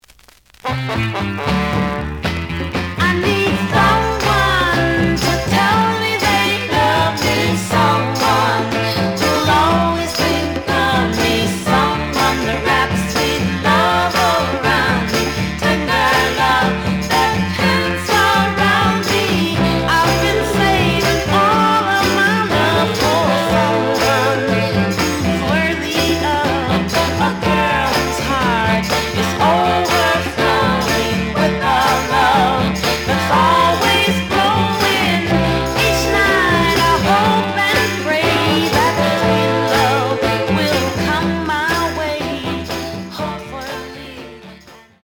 試聴は実際のレコードから録音しています。
●Genre: Soul, 60's Soul
傷は多いが、プレイはまずまず。)